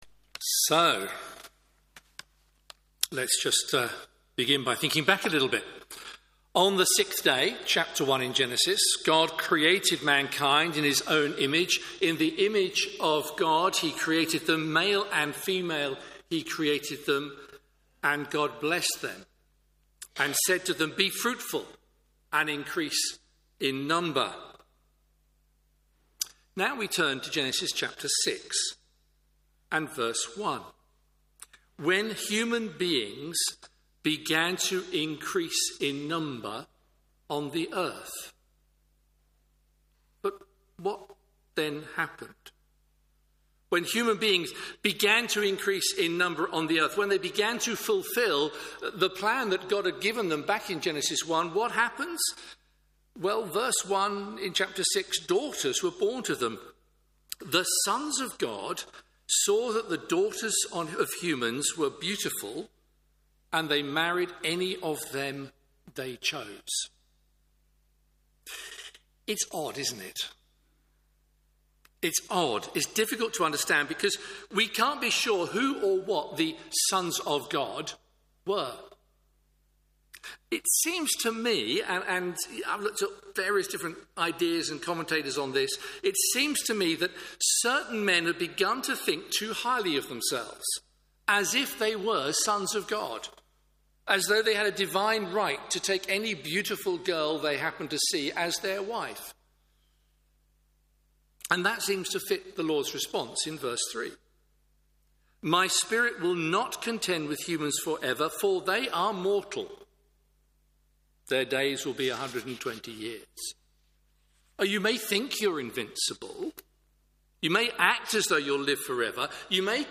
Media for Morning Service on Sun 13th Jul 2025 10:30 Speaker: Passage: Genesis 6-7 Series: Genesis Theme: Sermon In the search box please enter the sermon you are looking for.